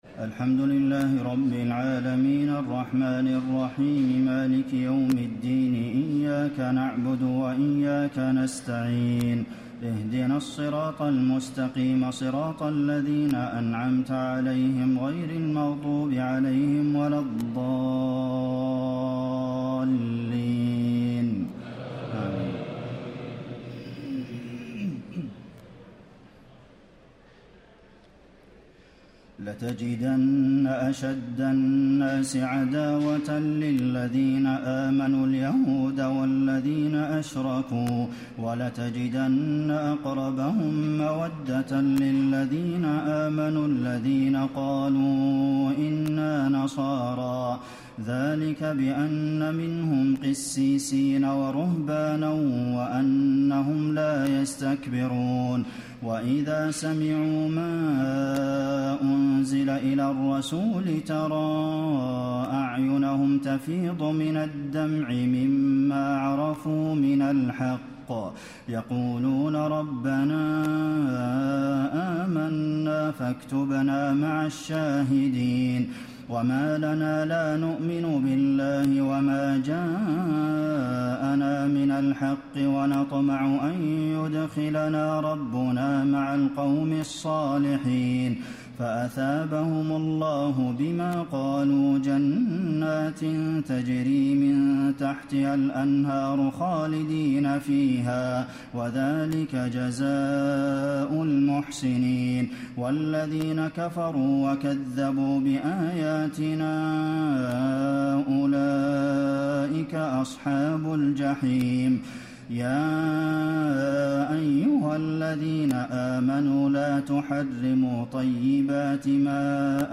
تهجد ليلة 27 رمضان 1435هـ من سورتي المائدة (82-120) و الأنعام (1-58) Tahajjud 27 st night Ramadan 1435H from Surah AlMa'idah and Al-An’aam > تراويح الحرم النبوي عام 1435 🕌 > التراويح - تلاوات الحرمين